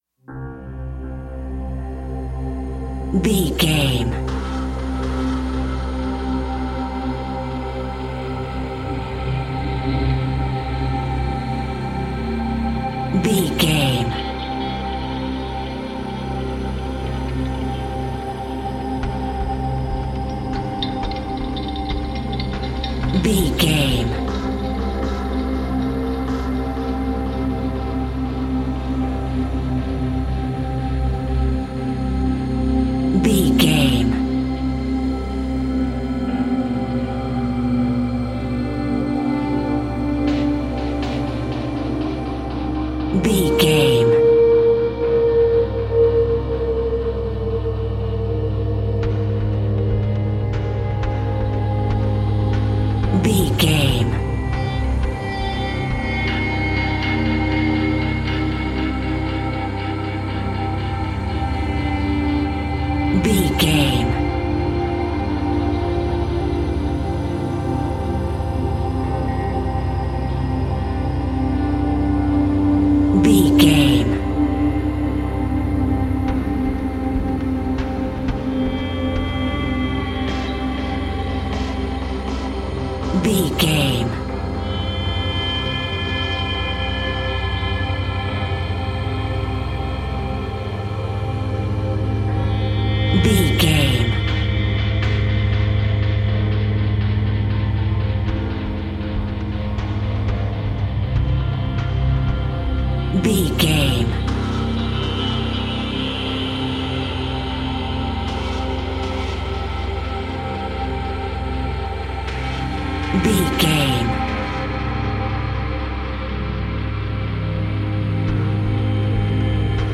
Thriller
Aeolian/Minor
synthesiser
percussion